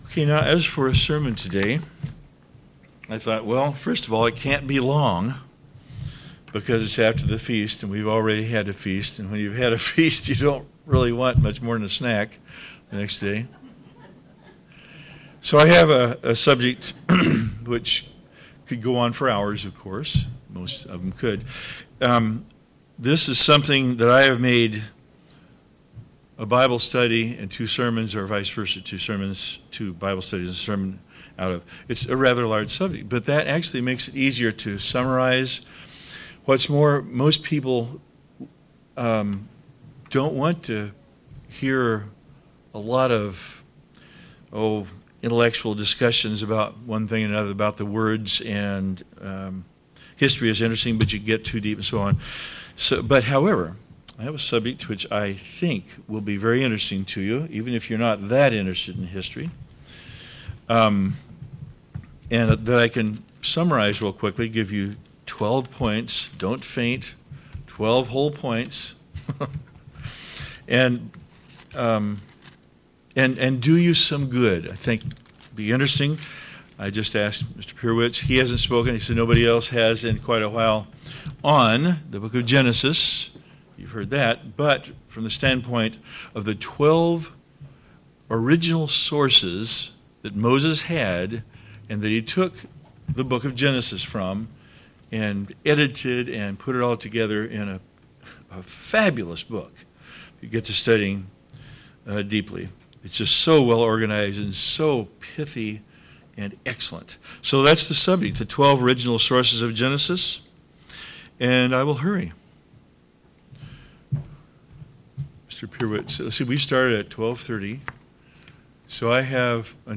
How the book of Genesis has come to be one book has been a topic discussed and argued for quite some time between scholars and critics of the Bible. In this sermon, the speaker looks into a theory, known of hand as the tablet theory, where the book of Genesis was compiled mainly by Moses from 12 different tablets, all still inspired by God.